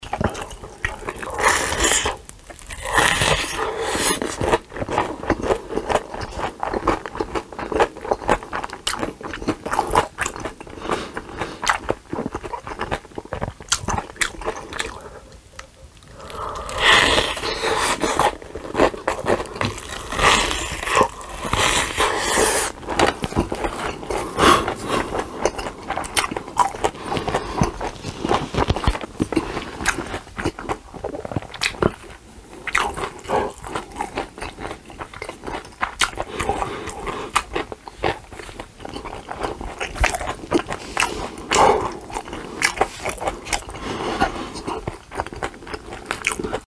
Звуки еды
Жевание пищи с причмокиванием